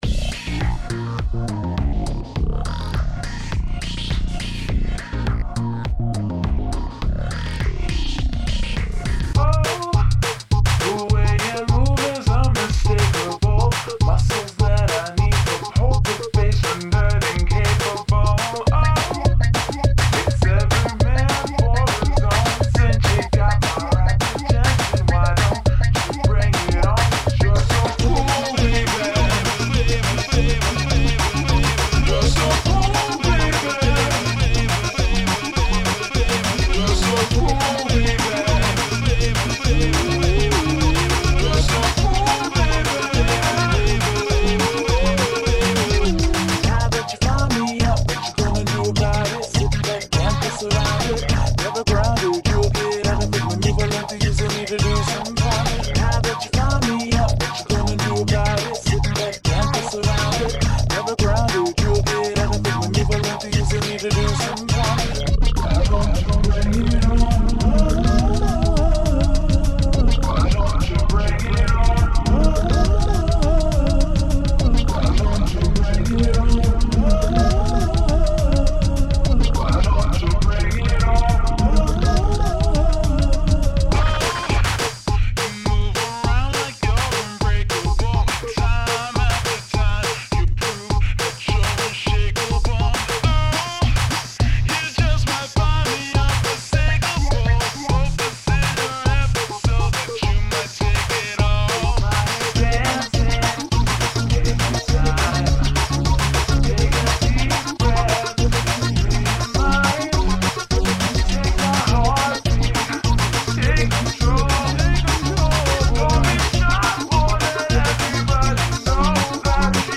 A remix EP